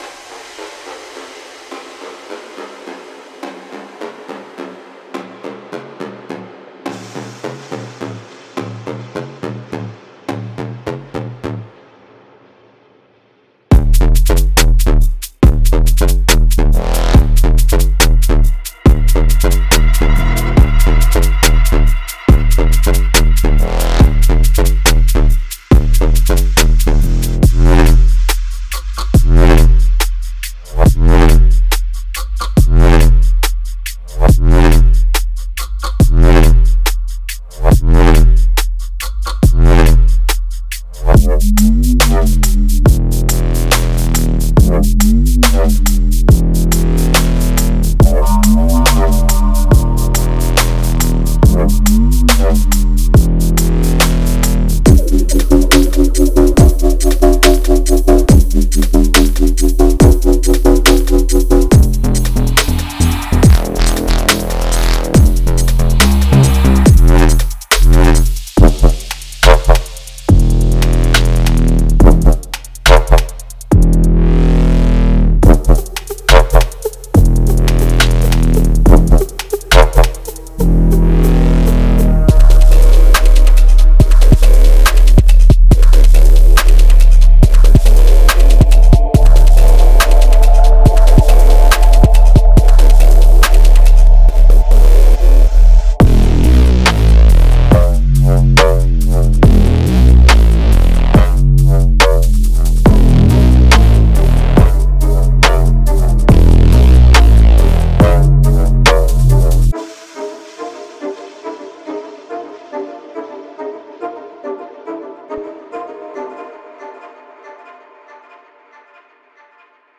Genre:Dubstep
精密な重みとグリミーな質感のバランスが取れており、前面に配置してもよし、より自由に切り刻んで使用してもよしです。
一部は豊かで広がりのあるサウンド、他はドライで閉鎖的なサウンドで、レイヤーや再構築に最適です。
デモサウンドはコチラ↓